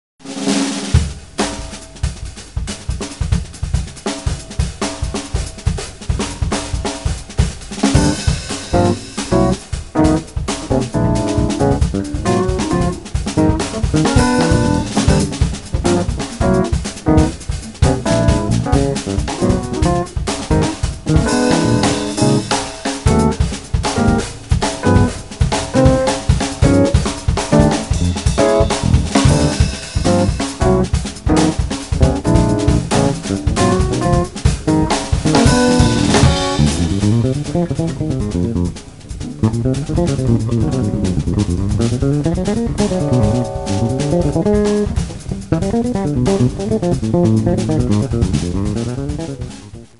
and electric bass
piano, organ, Fender Rhodes
drums
Riarmonizzazioni elettriche